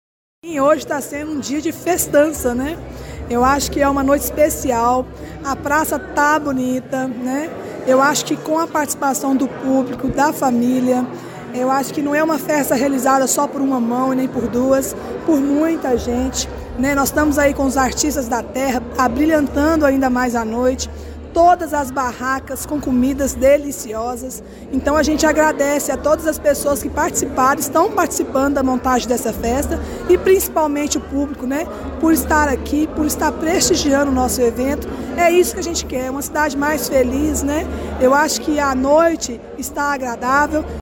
A secretária Andréia Xavier Paulino também esteve na praça e destacou ainda a decoração, que entrou no clima da festa: